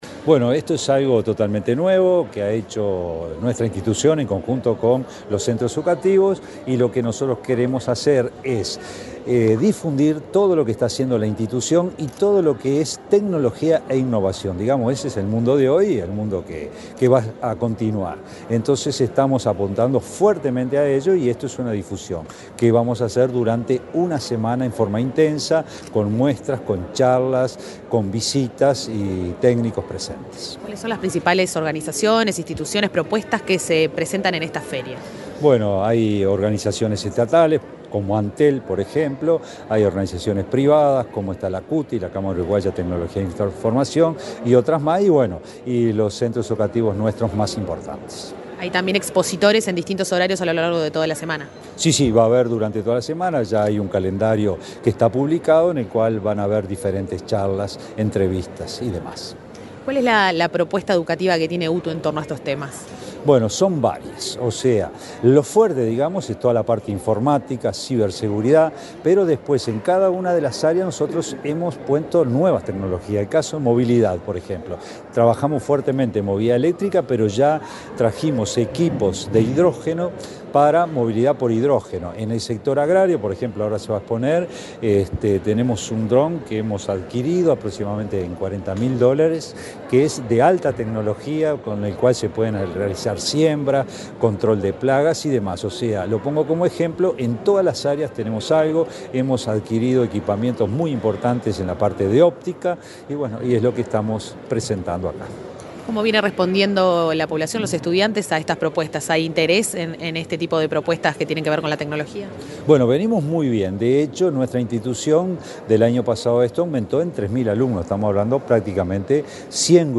Entrevista al director general de UTU, Juan Pereyra
Este lunes 22 en Montevideo, el director general de Educación Técnico Profesional - UTU, Juan Pereyra, dialogó con Comunicación Presidencial, antes de